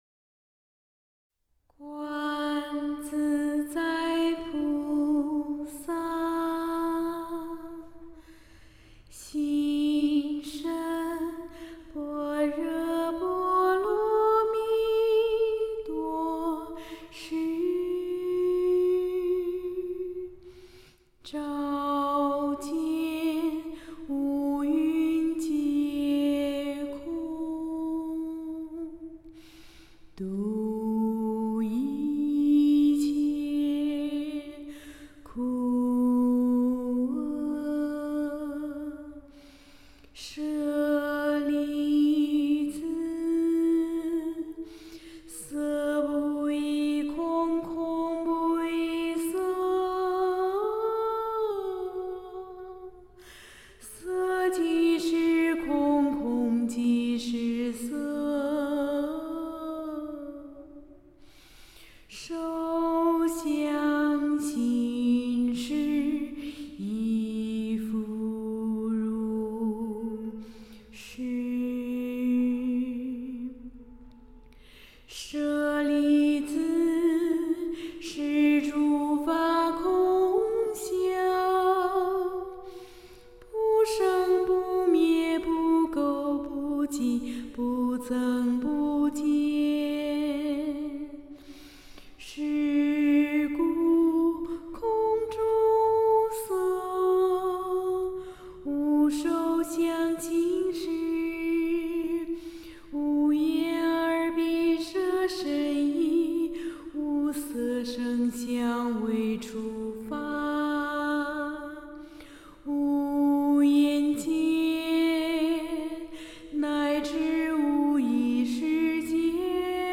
这是我们温泉村第五期同学唱的《心经》，大家听听